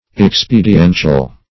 Search Result for " expediential" : The Collaborative International Dictionary of English v.0.48: Expediential \Ex*pe`di*en"tial\ Governed by expediency; seeking advantage; as an expediential policy.